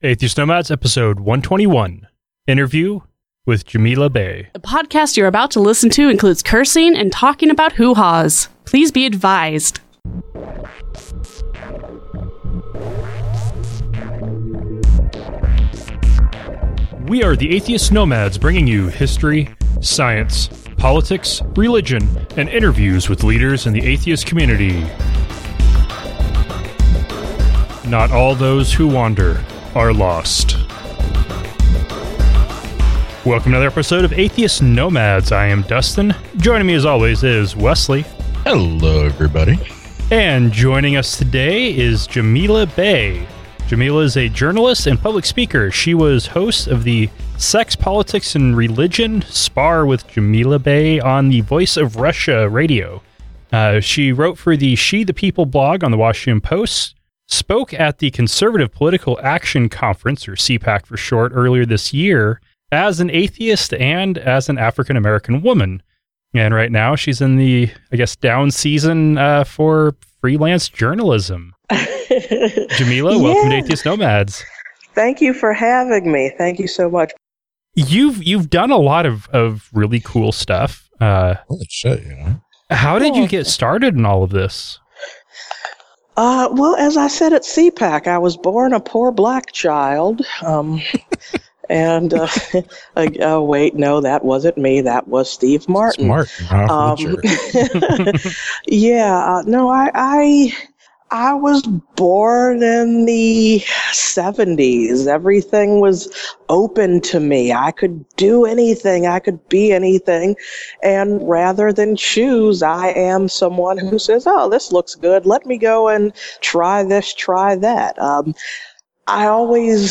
Episode 121 – Interview